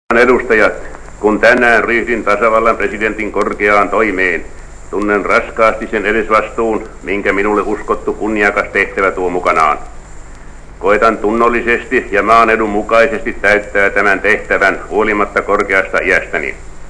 Die Rede wurde von Präsident Mannerheim anläßlich der Eröffnung des neuen Parlaments im April 1945 gehalten. Präsident Mannerheim verweist auf die harte Arbeit, die jetzt nach dem Ende des Krieges getan werden muß, um die Schäden des Krieges zu beseitigen.